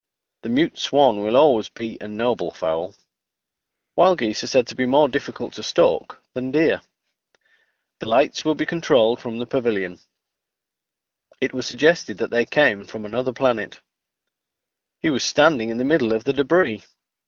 Door het hogere frequentiebereik, moet het geluid helderder klinken en achtergrondgeluid juist minder te horen zijn.
HD Voice
hd_male.mp3